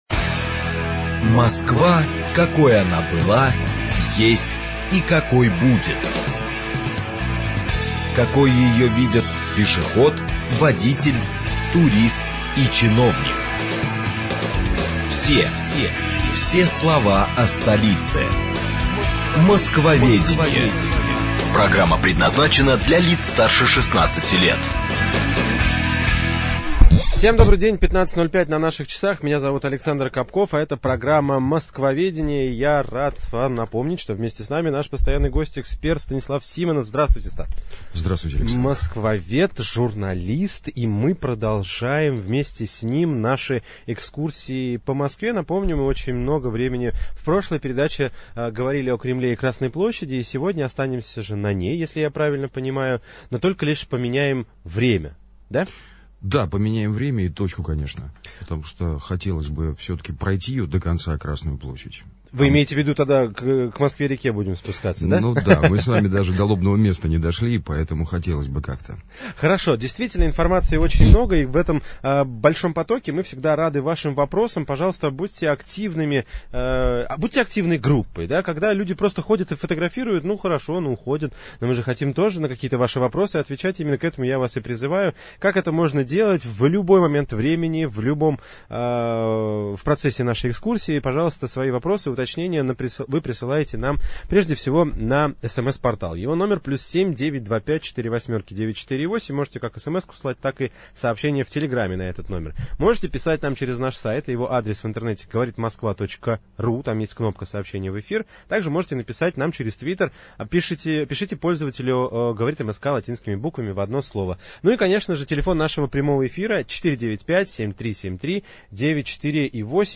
Aудиокнига Красная площадь. Часть 2 Автор Станислав Симонов.